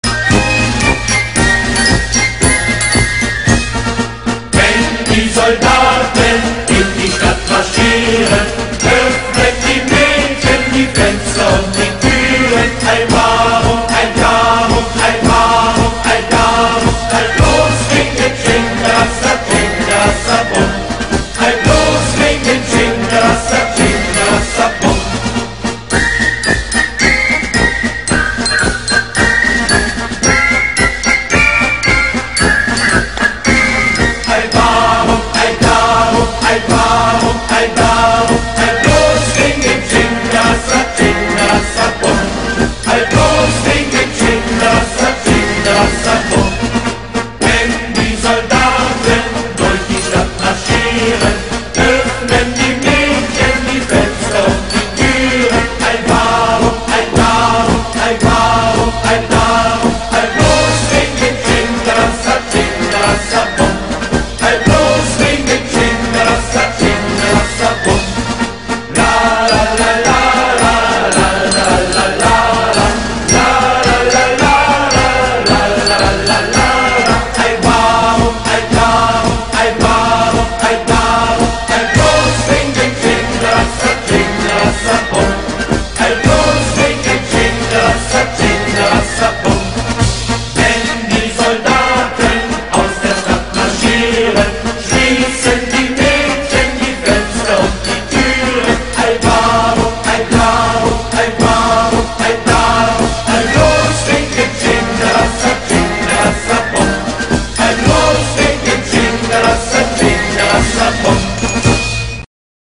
• Качество: 128, Stereo
инструментальные
звонкие